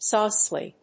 音節sáu・ci・ly 発音記号・読み方
/‐səli(米国英語)/